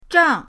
zhang4.mp3